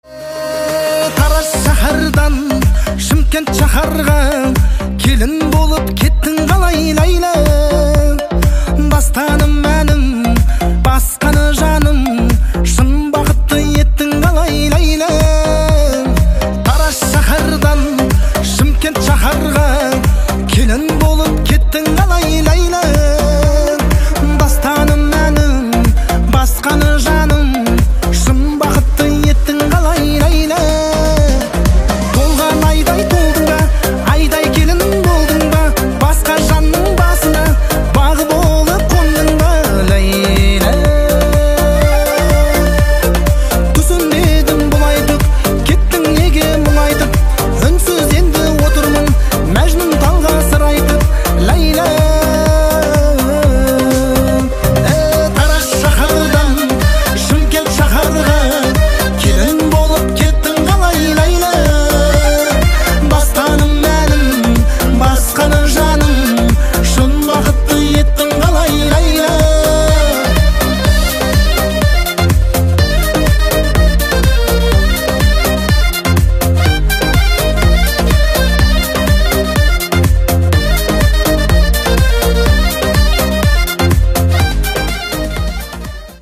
• Качество: 192, Stereo
мужской вокал
громкие
веселые
dance
Electronic
электронная музыка
аккордеон